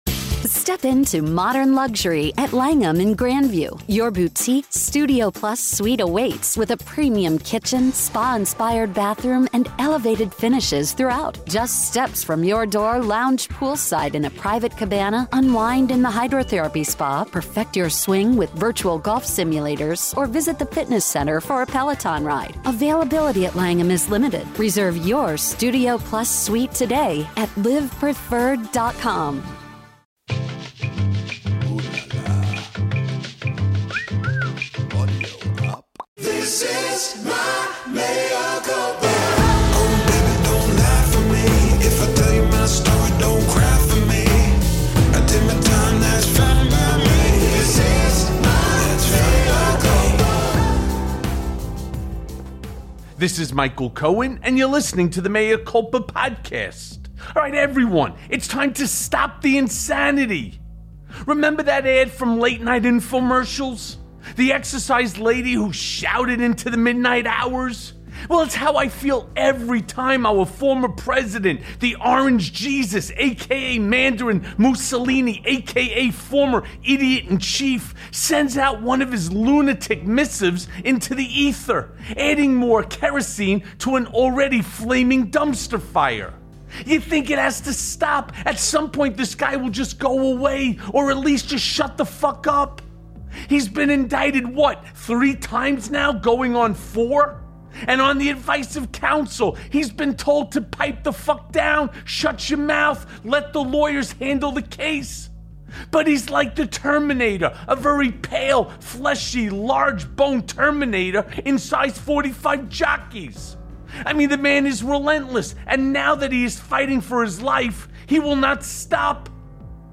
Mea Culpa welcomes podcaster, radio host, and columnist Joe Walsh. Walsh was elected to the House of Representatives in 2011 from Illinois’s 8th District.